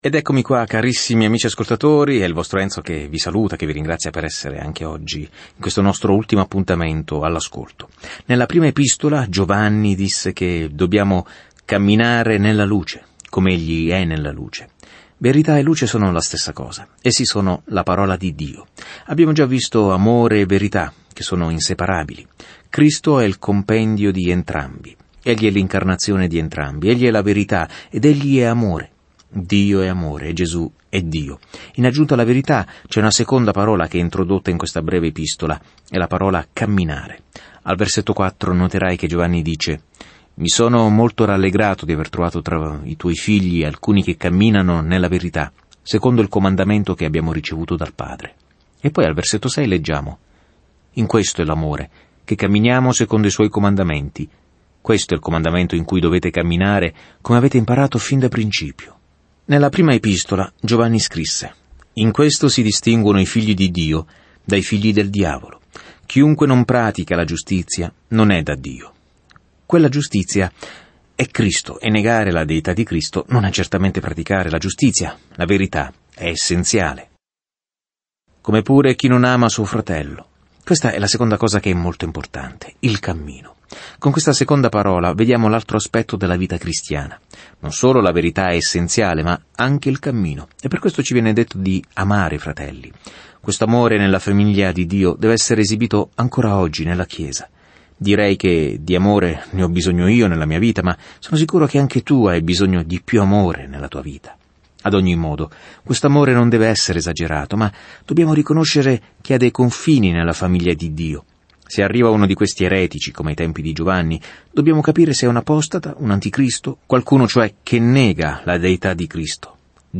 Scrittura Seconda lettera di Giovanni 1:1-3 Giorno 4 Comincia questo Piano Giorno 6 Riguardo questo Piano Questa seconda lettera di Giovanni aiuta una donna generosa, e una chiesa locale, a saper esprimere l'amore entro i confini della verità. Viaggia ogni giorno attraverso 2 Giovanni mentre ascolti lo studio audio e leggi versetti selezionati della parola di Dio.